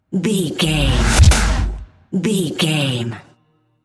Aeolian/Minor
drum machine
synthesiser
90s
Eurodance